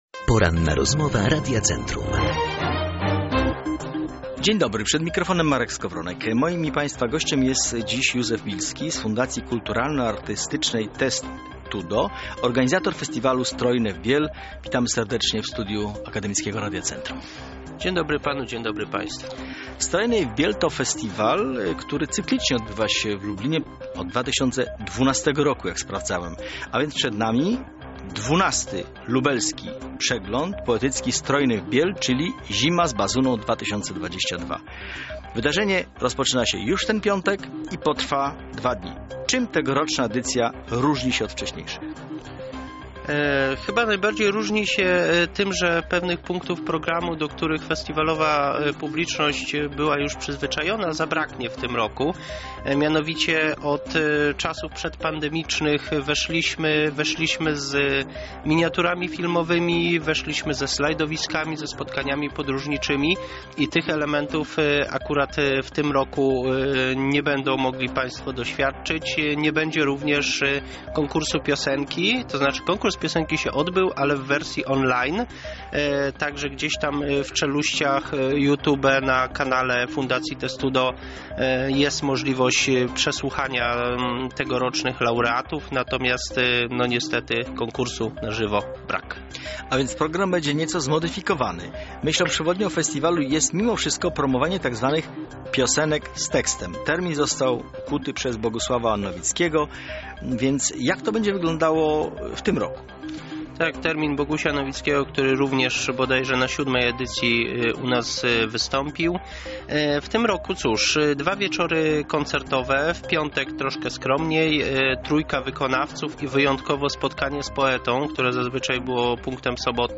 Opublikowano w Poranna Rozmowa Radia Centrum